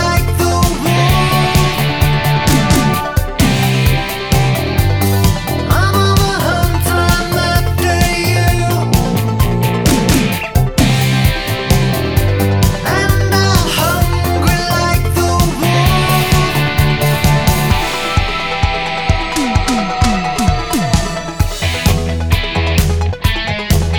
Two Semitones Down Pop (1980s) 3:35 Buy £1.50